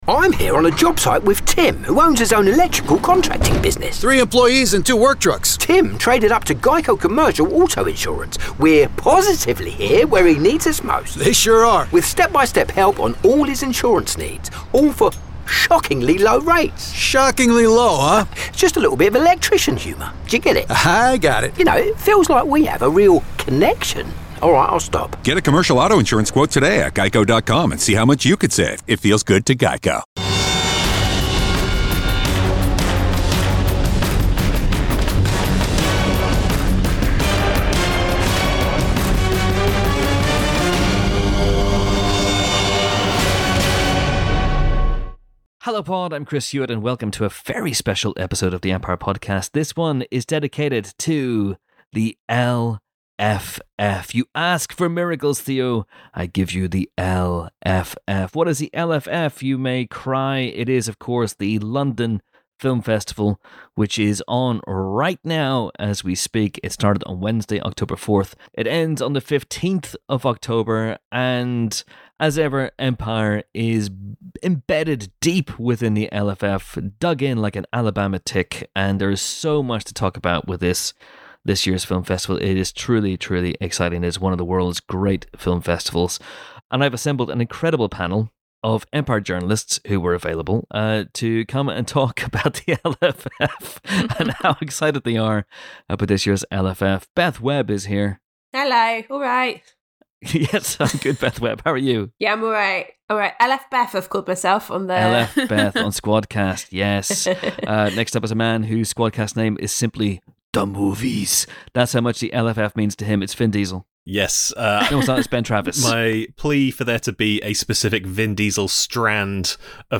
Film Reviews